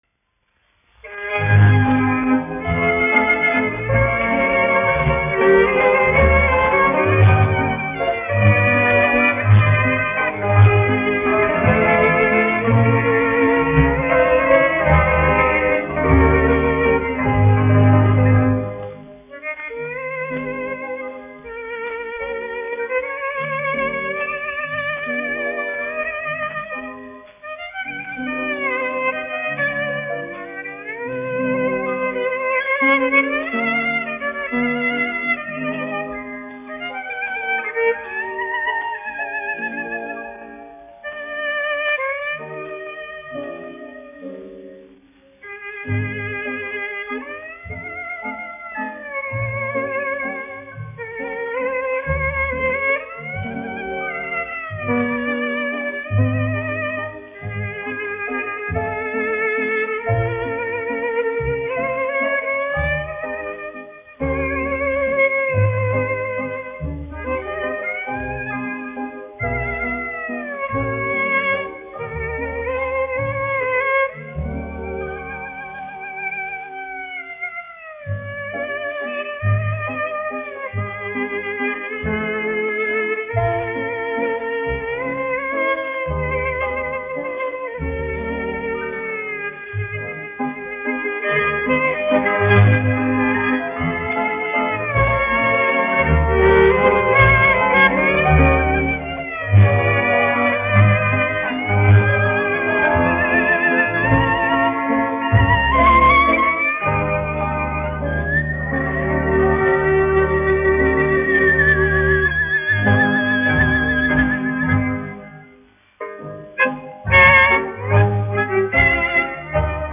1 skpl. : analogs, 78 apgr/min, mono ; 25 cm
Romances (mūzika)
Čigānu mūzika
Latvijas vēsturiskie šellaka skaņuplašu ieraksti (Kolekcija)